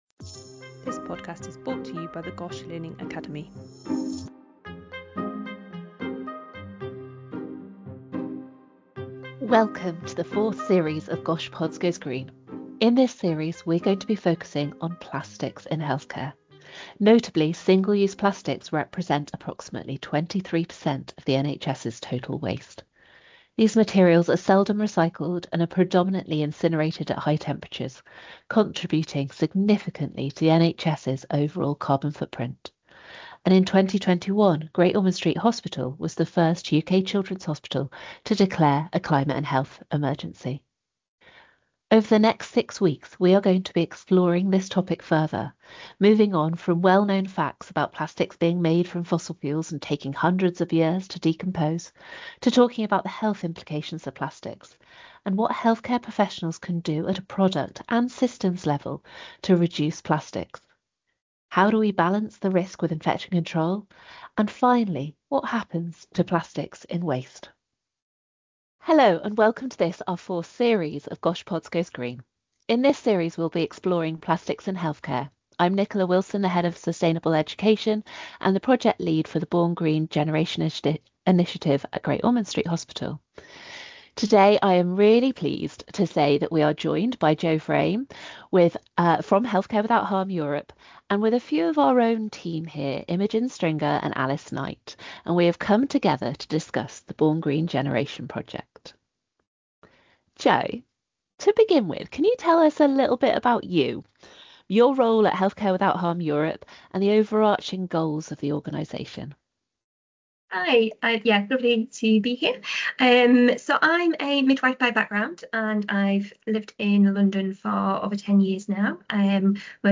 Our guests discuss why Born Green goes far beyond carbon reduction, the enthusiasm from staff across maternity and paediatric hospitals across Europe, and the practical challenges of making sustainable change in busy clinical environments.